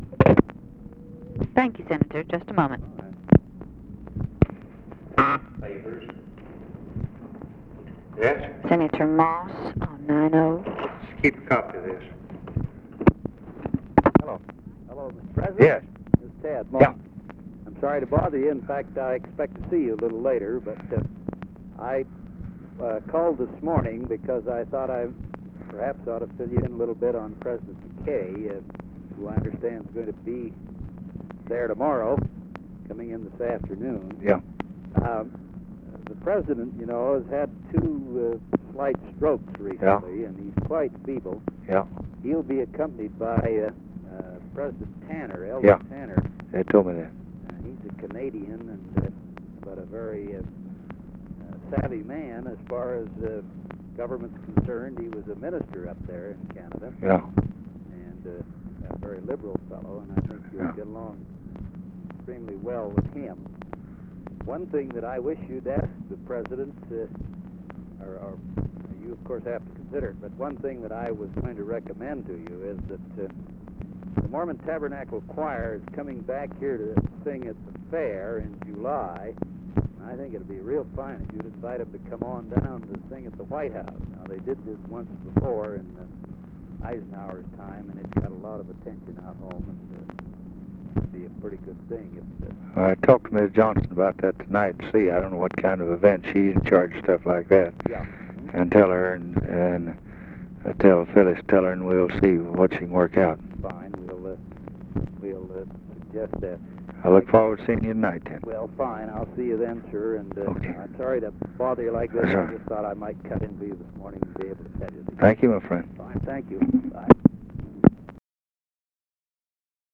Conversation with FRANK MOSS, January 30, 1964
Secret White House Tapes